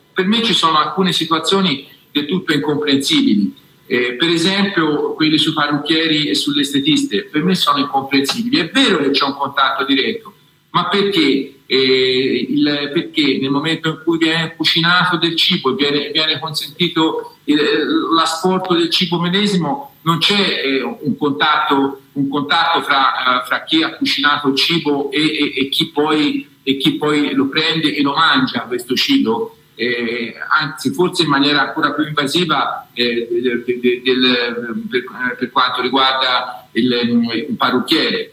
In videoconferenza con i media locali il primo cittadino ha fatto il punto sugli scenari che ci aspetteranno nel mese di Maggio.
DE-MOSSI-PARRUCCHIERE.mp3